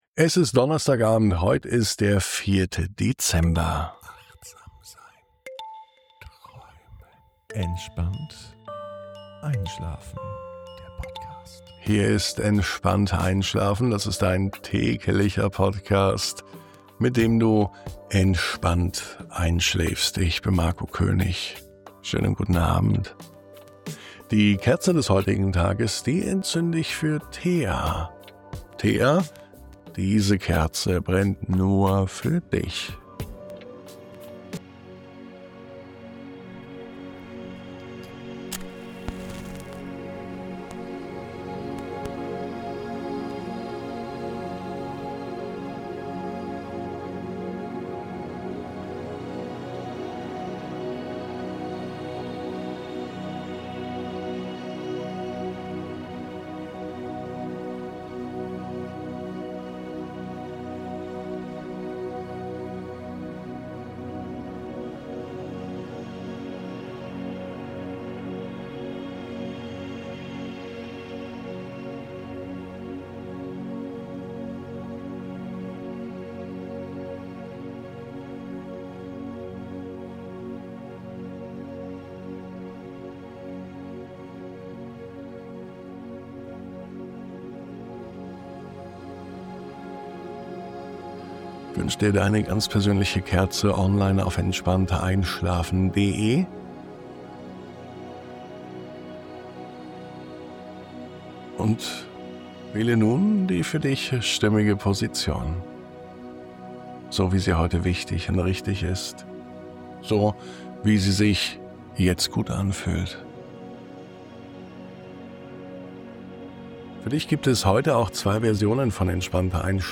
Diese Traumreise hilft dir, abzuschalten, loszulassen und entspannt in den Schlaf zu gleiten.